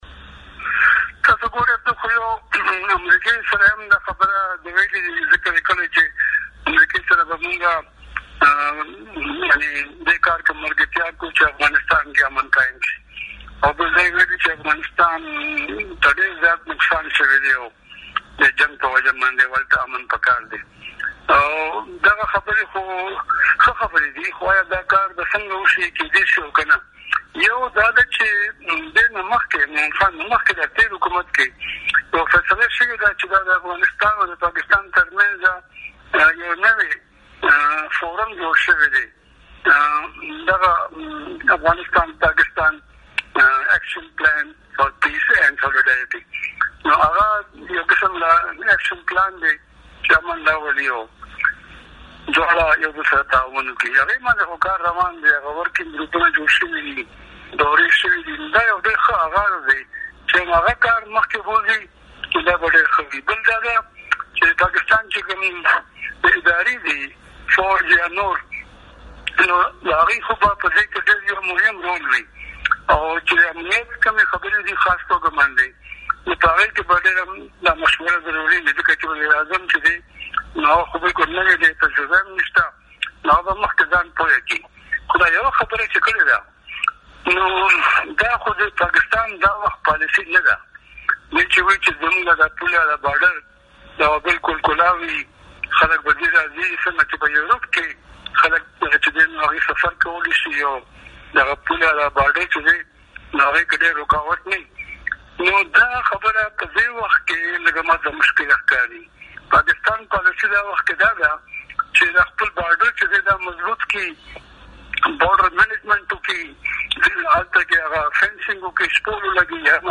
د رحيم الله يوسفزي وي او ای ډيوه سره مرکه